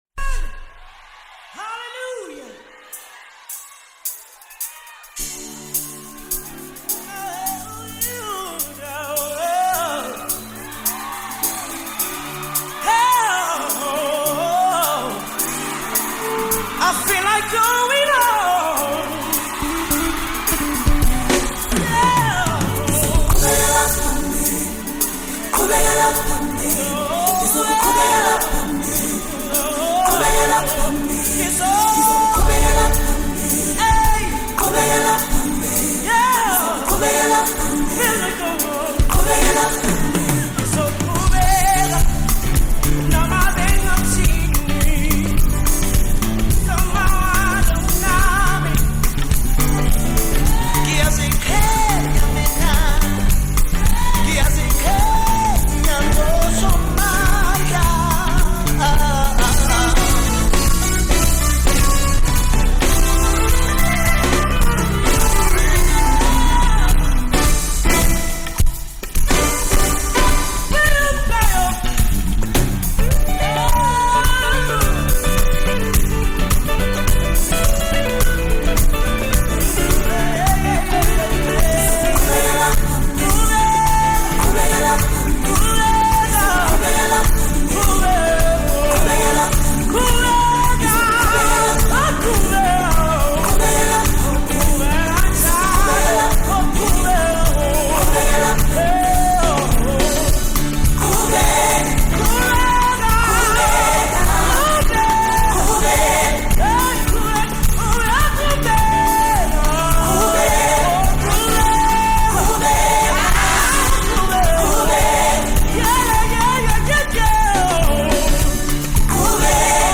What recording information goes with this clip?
recorded live in the year 2024